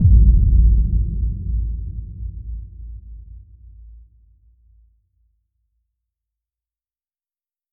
Cinematic_Boom_Rhapsodize
bang bomb bombs boom cinematic cinematic-boom climax distortion sound effect free sound royalty free Movies & TV